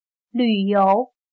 旅游 / lǚyóu /viajar